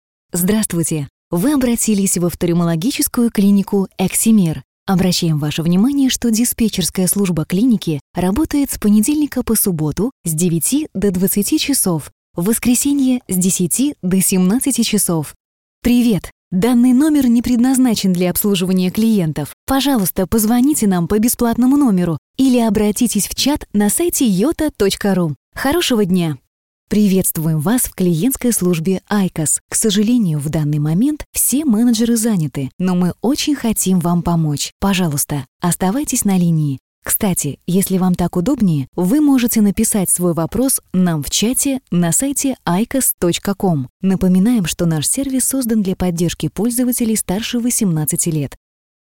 Примеры голосовых приветствий
Eksimer_golosovoe_privetstvie.mp3